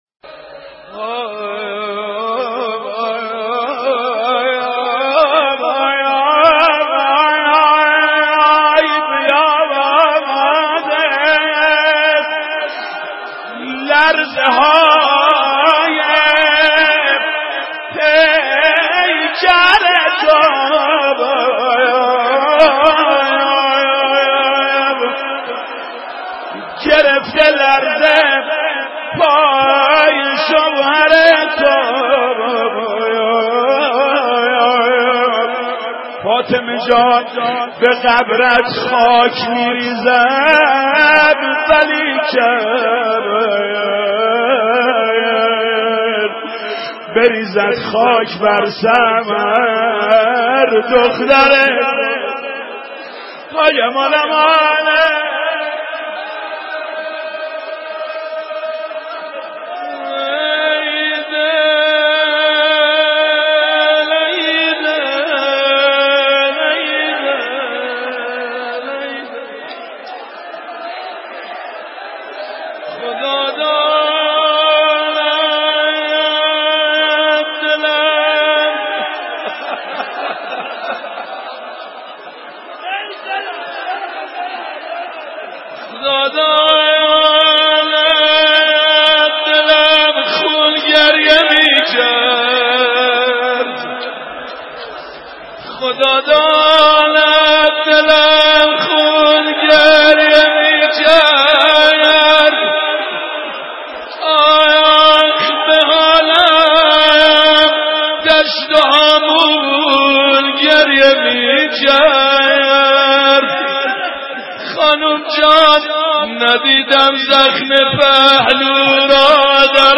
دانلود مداحی الا ای چاه یارم را گرفتند - دانلود ریمیکس و آهنگ جدید
روضه خوانی در ایام شهادت حضرت زهرا(س) توسط محمود کریمی (6:12)